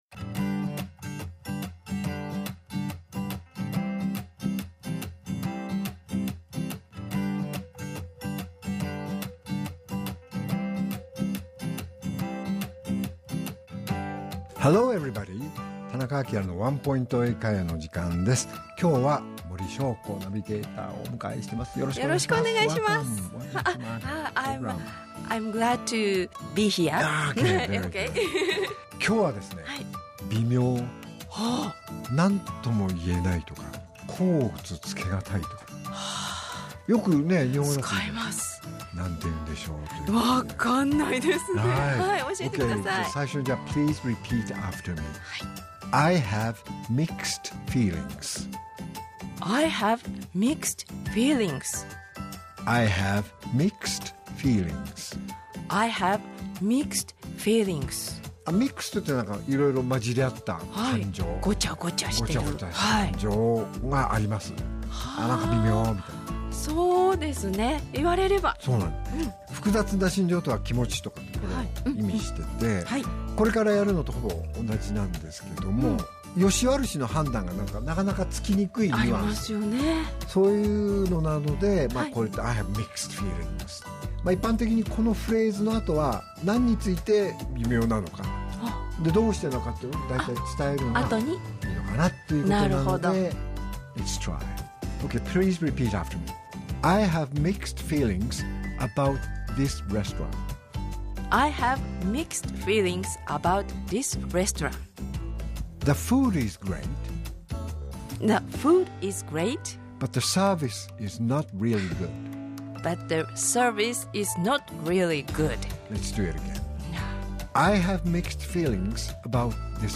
R7.11 AKILA市長のワンポイント英会話